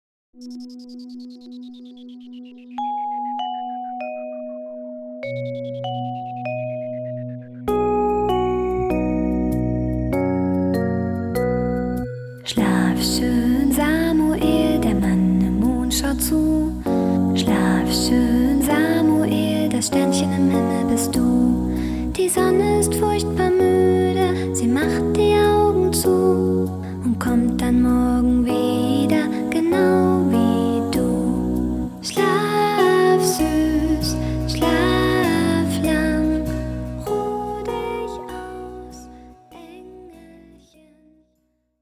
Personalisierte Kinderlieder für den ganzen Tag.
von einer wunderschönen Stimme bezaubern